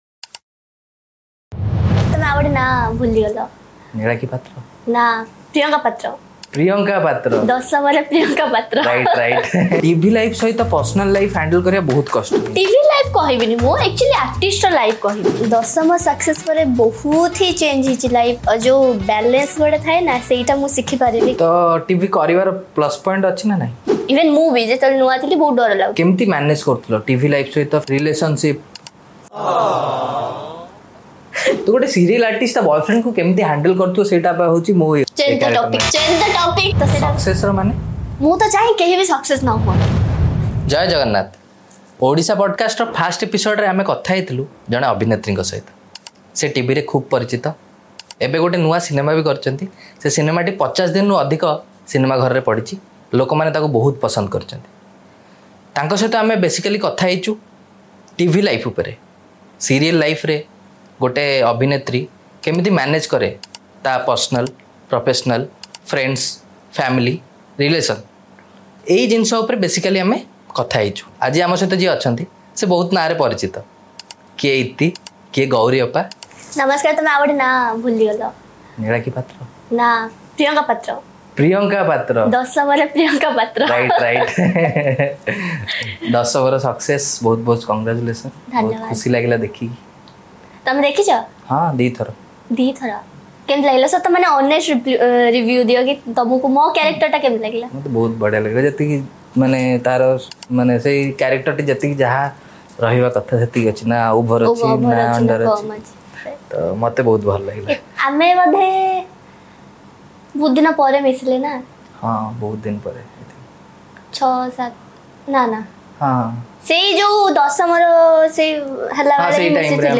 Welcome to the Odisha podcast, your destination for uncut, unfiltered conversations with inspiring personalities. Join us as we uncover their journeys, delve into their work, and explore the legacies they're building.